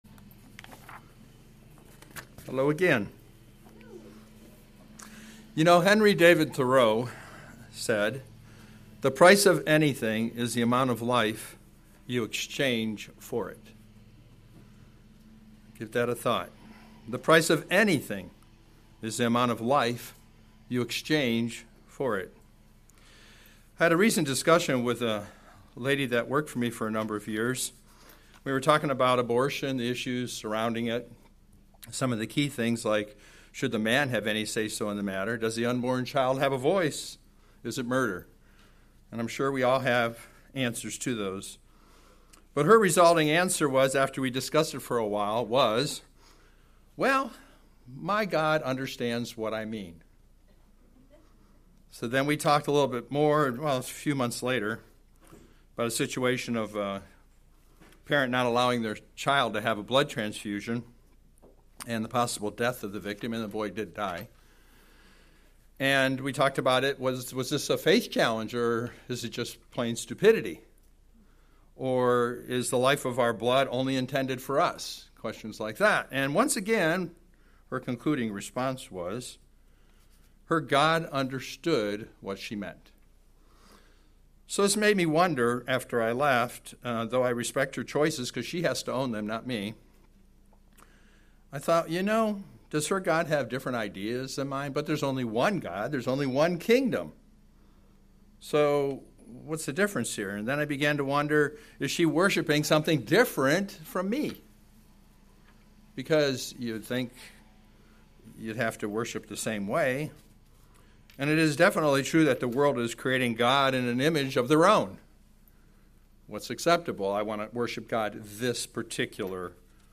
Sermons
Given in St. Petersburg, FL